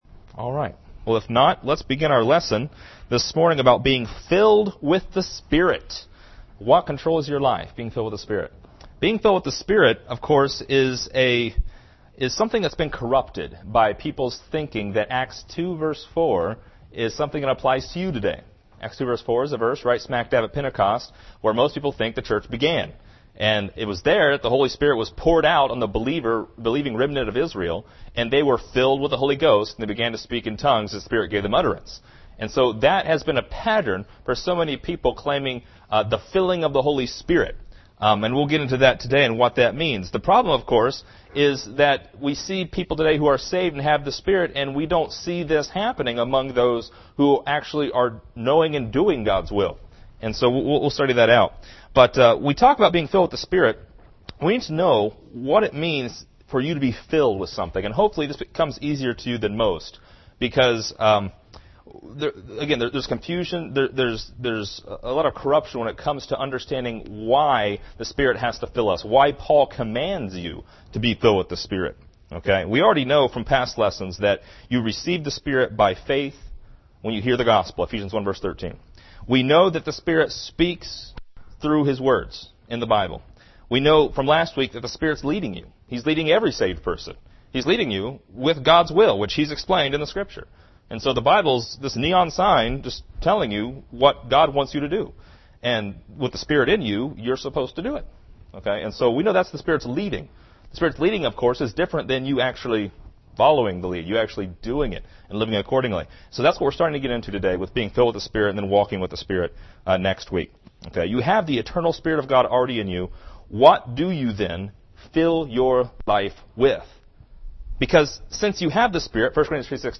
This is the sixth lesson in a series about How the Holy Ghost Works. Learn what it means to be filled with the Spirit and to be filled with the Spirit today.